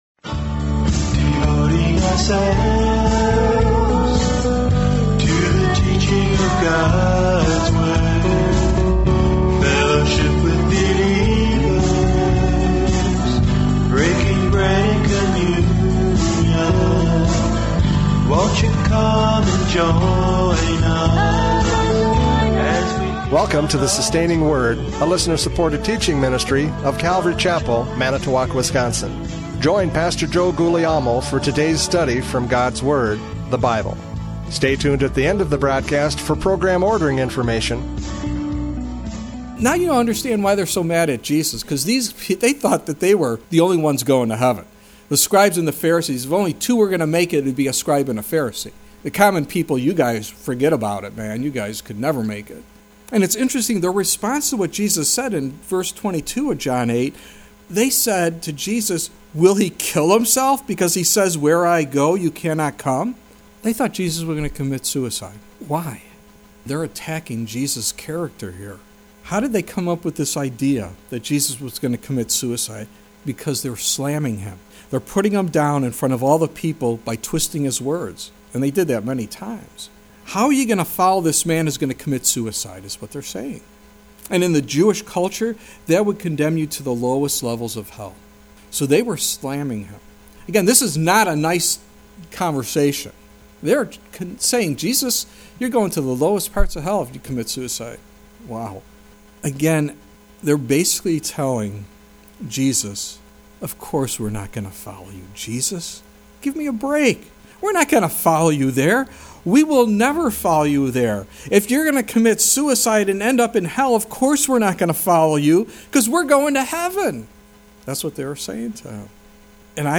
John 8:21-30 Service Type: Radio Programs « John 8:21-30 Life and Death!